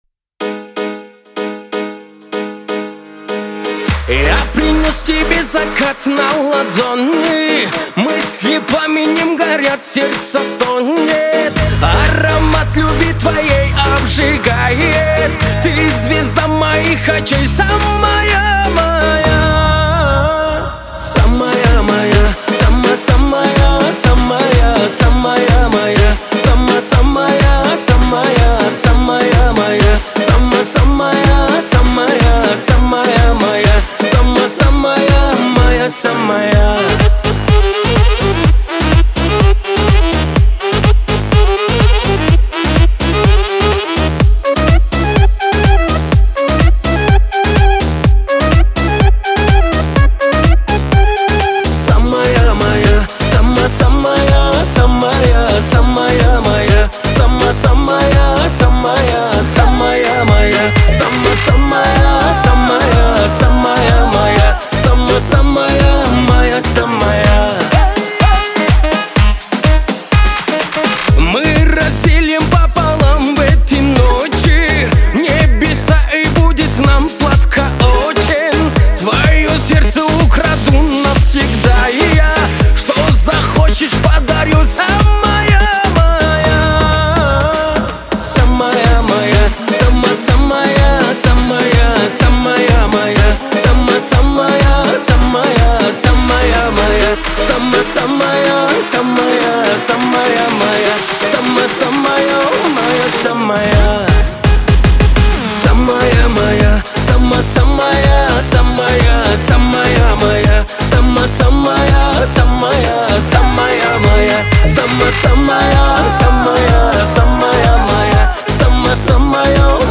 это трек в жанре поп с элементами казахской музыки